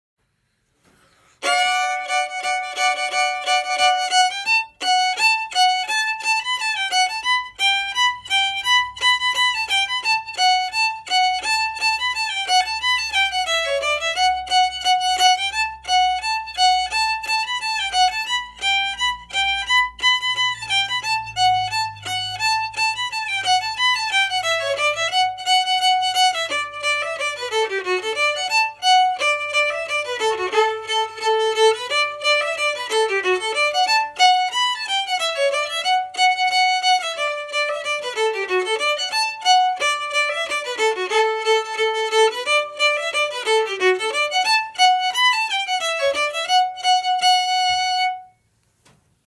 Liberty harmony (audio MP3)Download
liberty-harmony.mp3